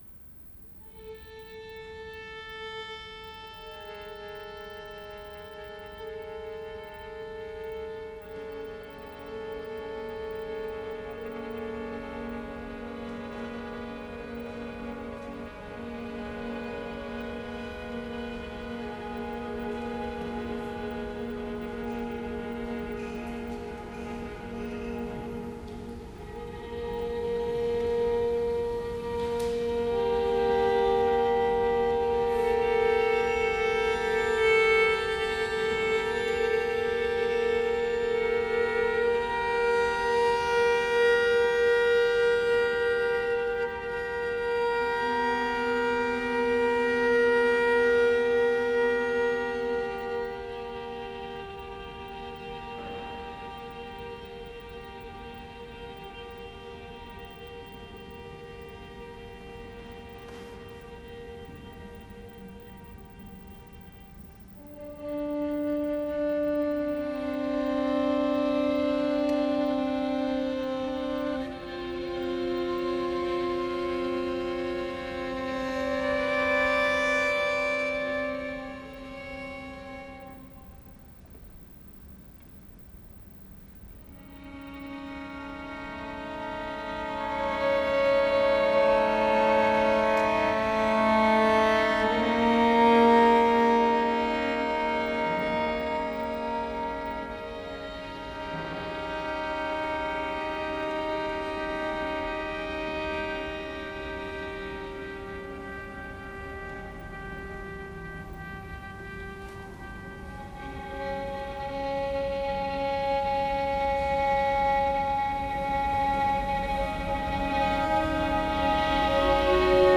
Barbican, 16-18 January 2004